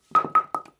bowlingPinFall_2.wav